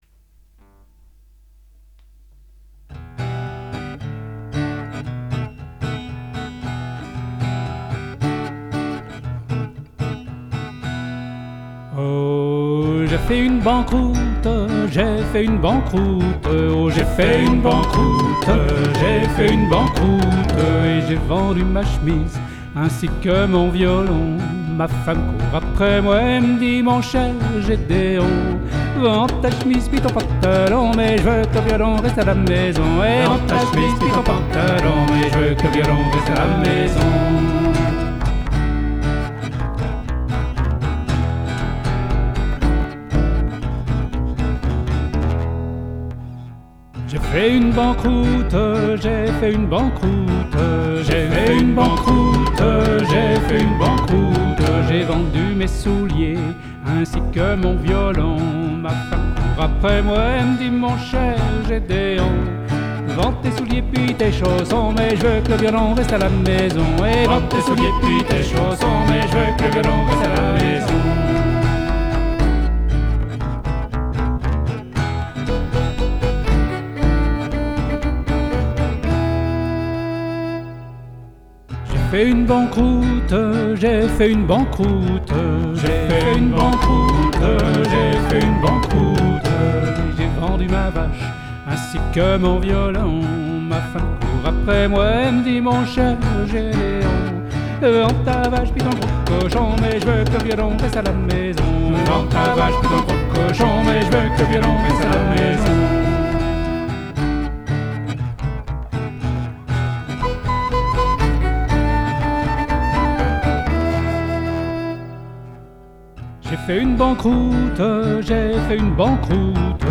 Traditionnel Québec